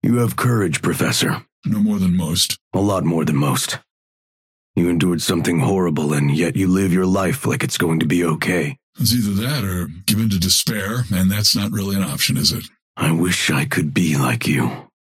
Dynamo and Grey Talon conversation 2